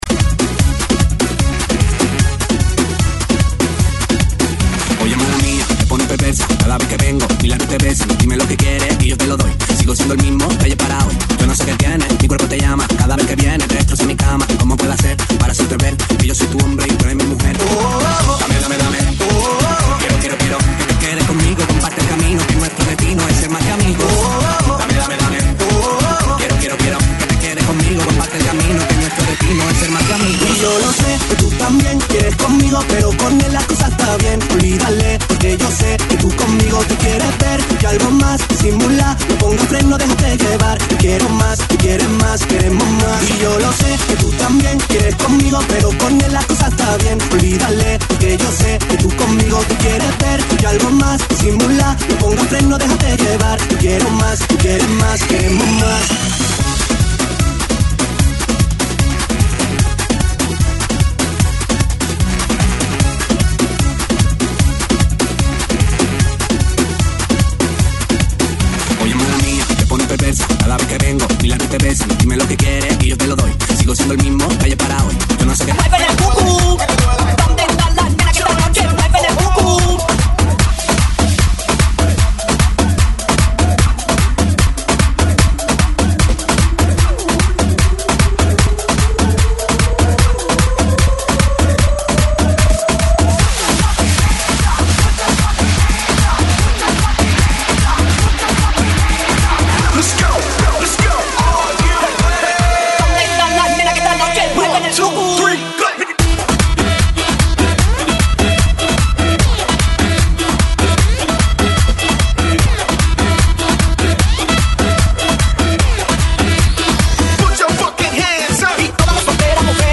GENERO: LATINO REMIX